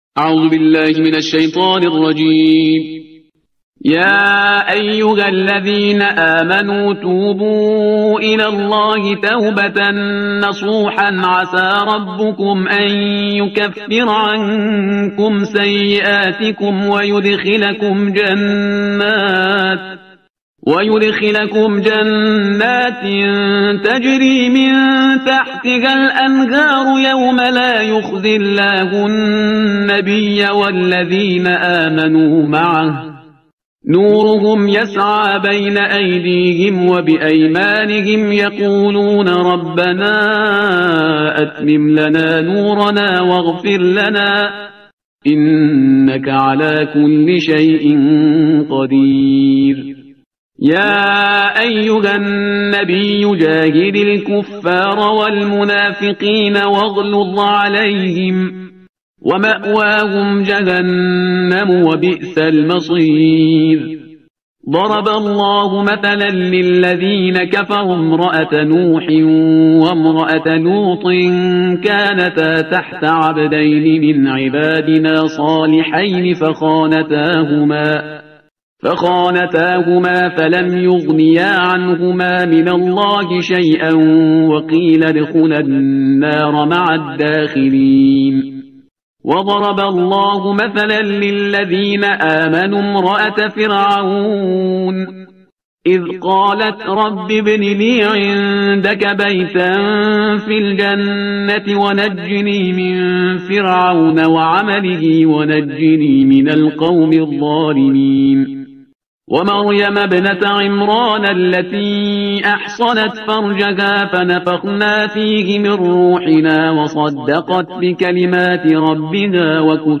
ترتیل صفحه 561 قرآن